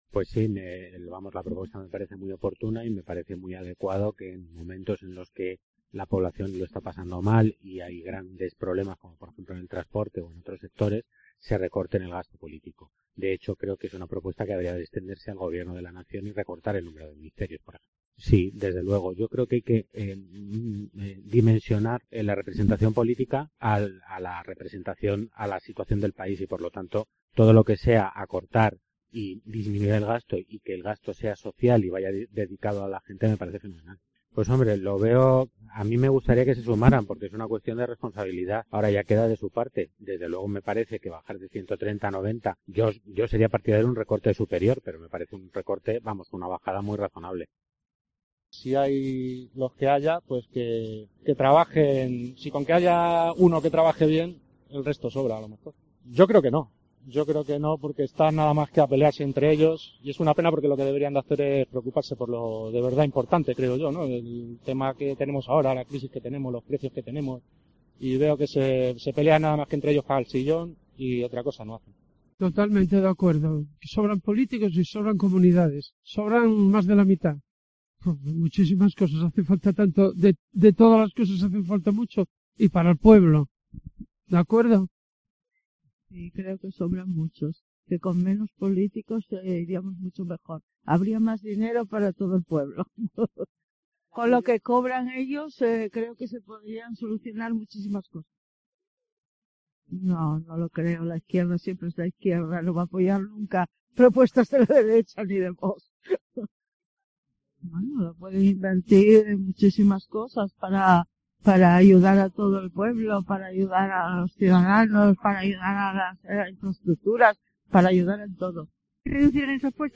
El debate sobre la reducción de diputados en la Asamblea de Madrid ha llegado a la calle.